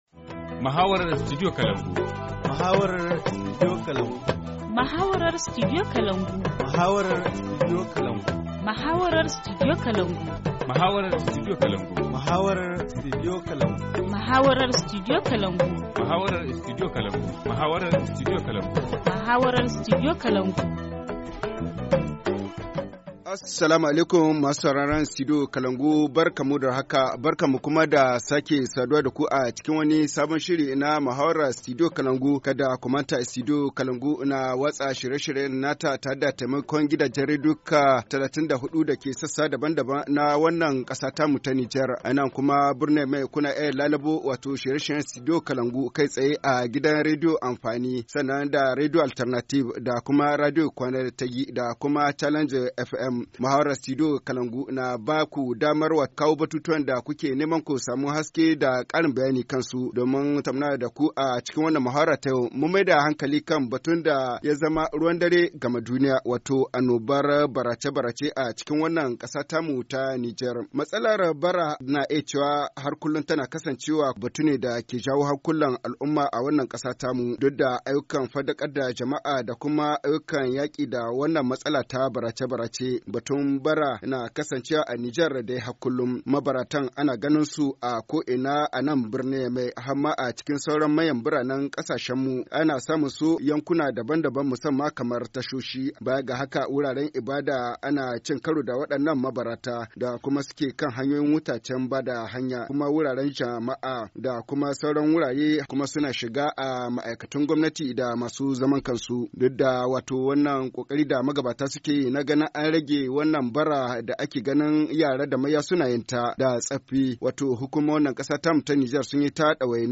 Forum Haoussa 10/02/2018 : Fléau de la mendicité au Niger - Studio Kalangou - Au rythme du Niger